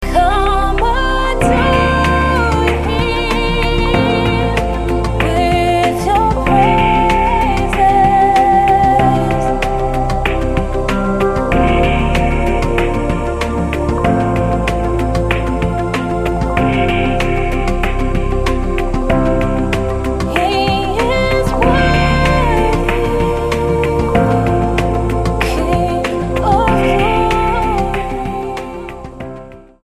STYLE: Ambient/Meditational
keys, sax, trumpet, guitars and drum loops